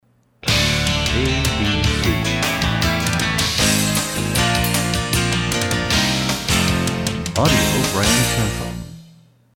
Genre: Jingles.